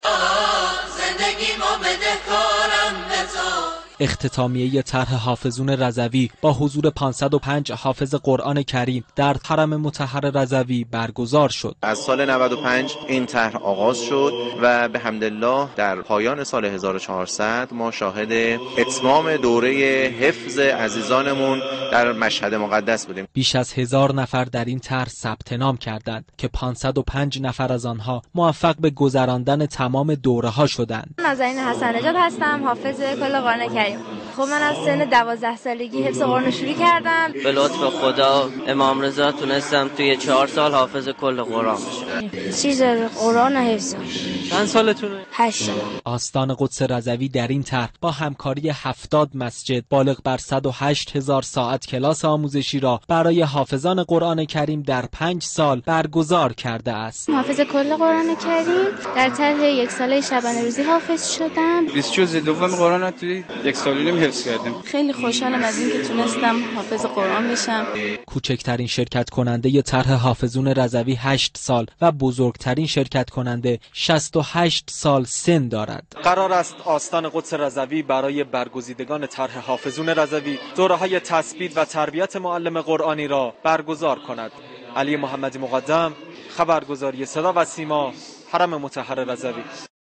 آیین اختتامیه طرح «حافظون رضوی» با تجلیل از 505 حافظ قرآن كریم در حرم مطهر رضوی برگزار شد.